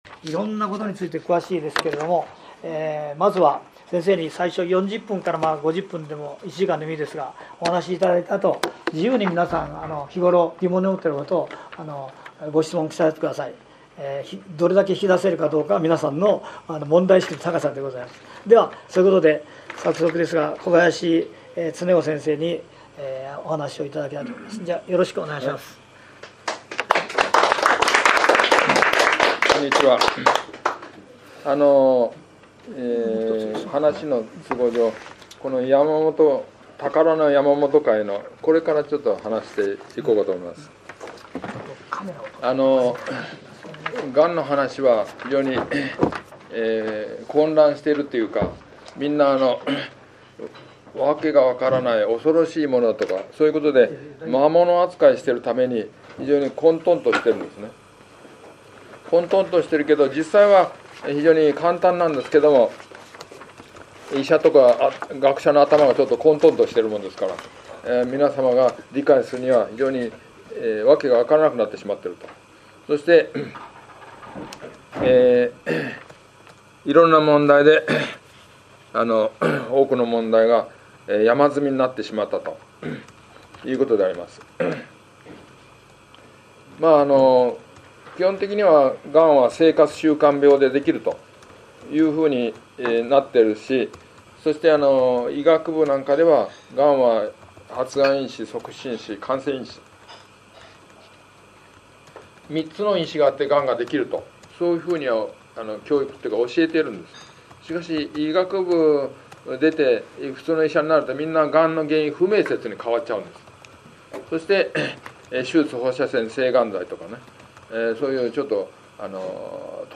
百鶴苑 1階ホール
講演「長寿の秘訣・がんの予知・予防について」